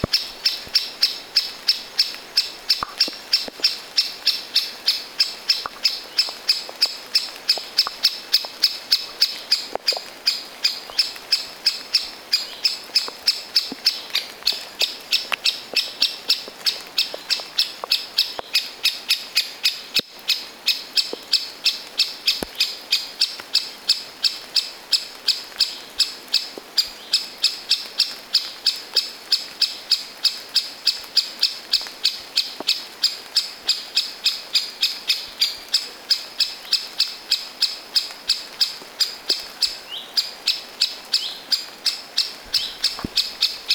käpytikka huomioääntelee
kiivaasti pesällään
kapytikka_pesallaan_kiivaasti_huomioaantelee.mp3